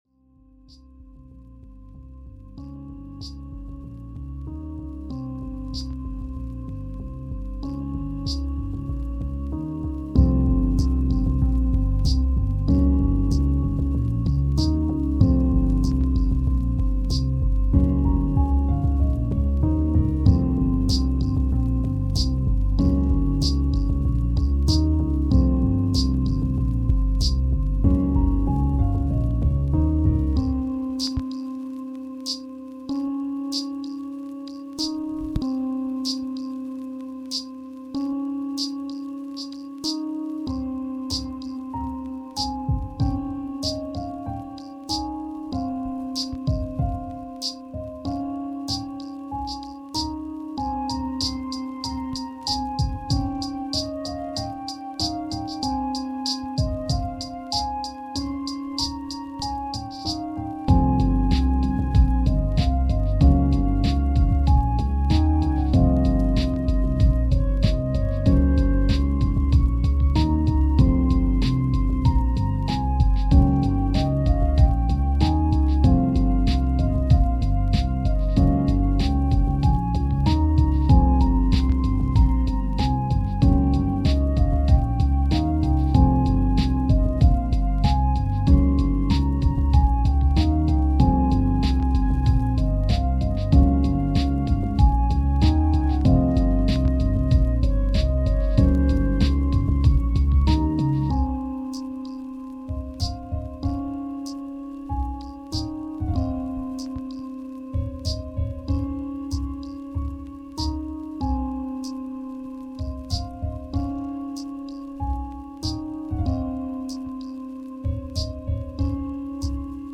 My first LoFi song!2 versions one that fades in and out and one that doesn't. Both loop, however the no-fade version is probably better for looping but depends on how it's used.